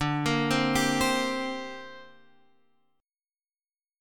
D7sus4#5 chord